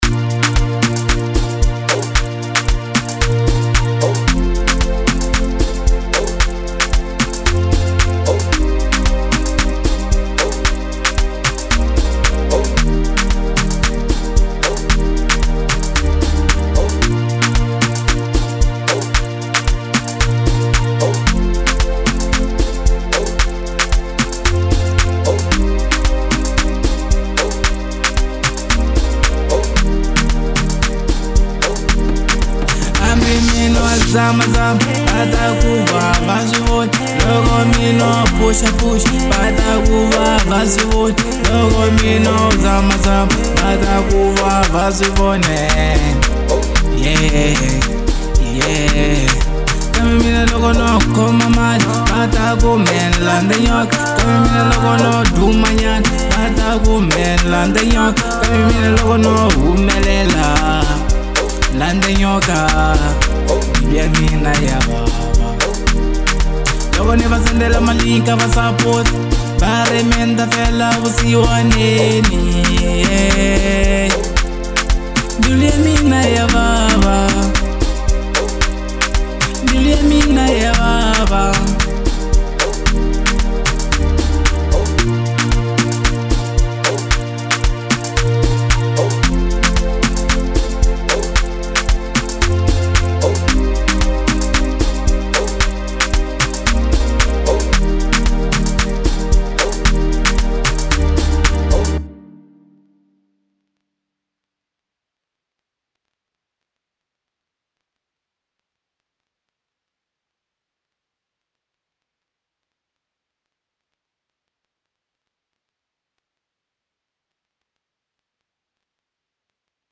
02:11 Genre : House Size